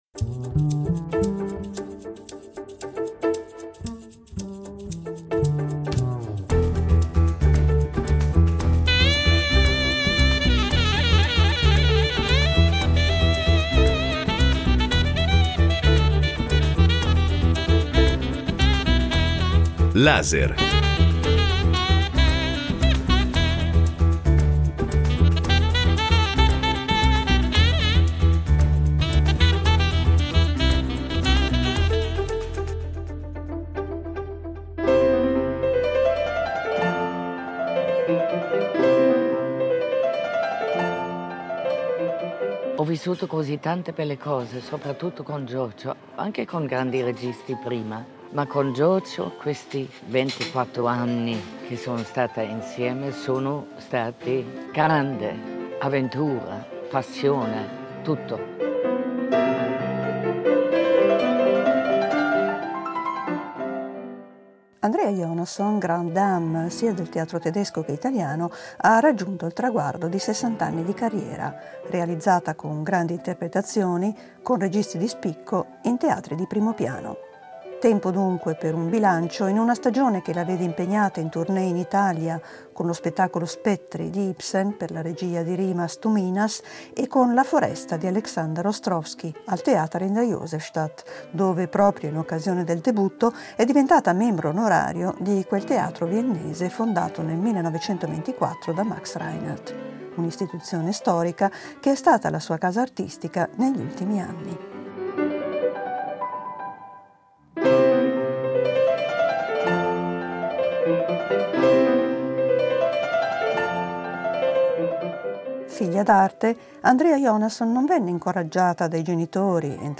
Una grande attrice europea, un repertorio teatrale vasto e variegato, una carriera lunga 60 anni, che l'ha portata sui maggiori palcoscenici di lingua sia tedesca che italiana. Sullo sfondo di un caffè viennese